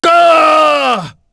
Phillop-Vox_Dead.wav